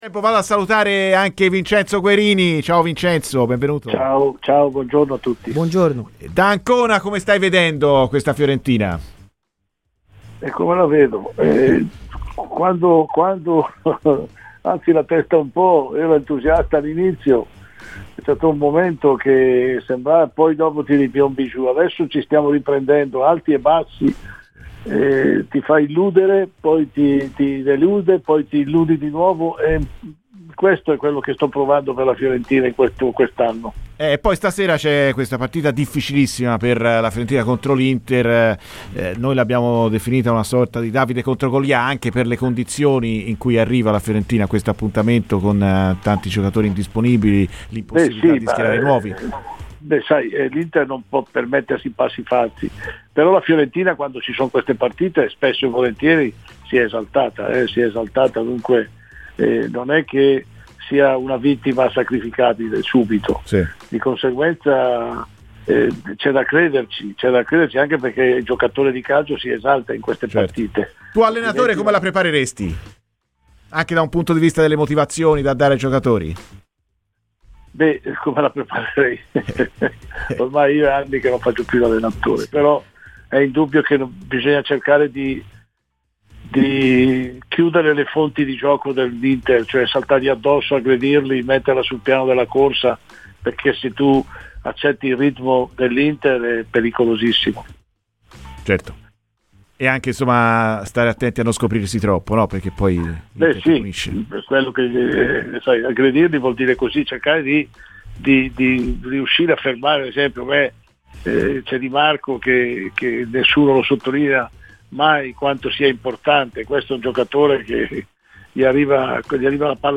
è intervenuto ai microfoni di Radio FirenzeViola durante la trasmissione "Viola amore mio".